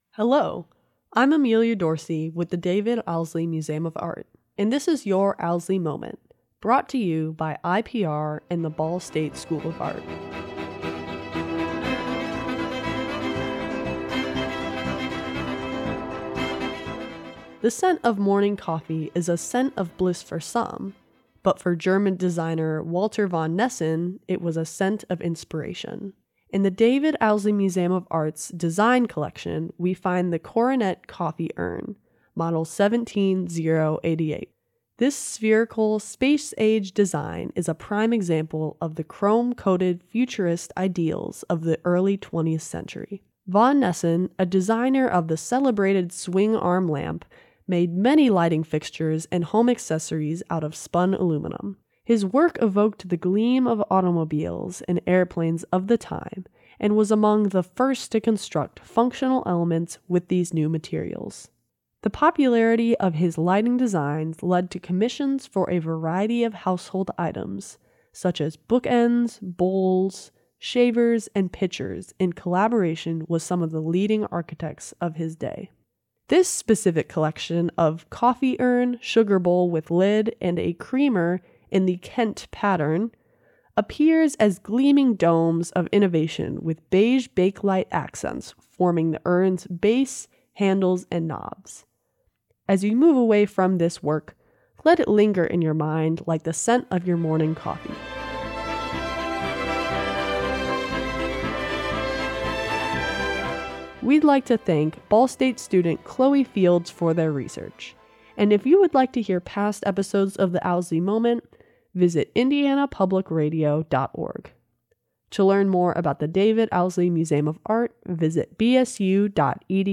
Music used in this episode:
Performed by the Michael Nyman Band and the Royal Liverpool Philharmonic Orchestra
They are produced in collaboration with the David Owsley Museum of Art and Indiana Public Radio and are voiced by Ball State students.